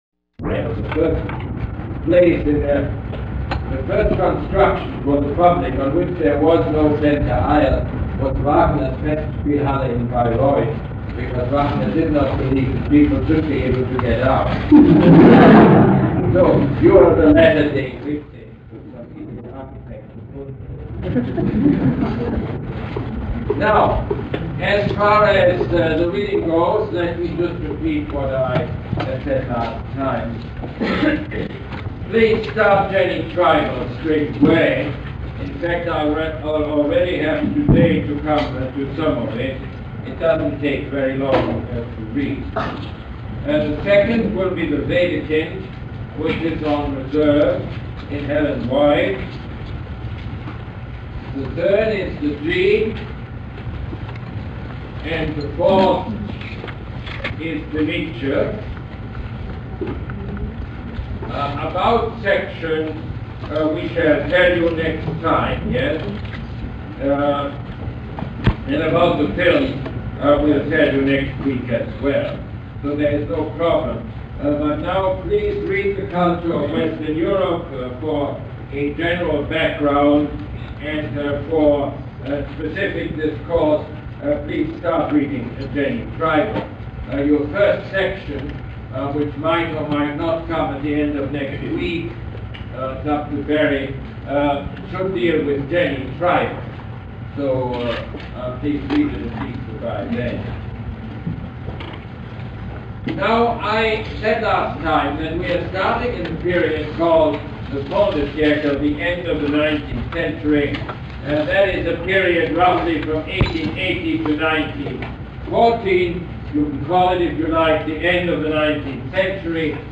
Lecture #1 - September 7, 1979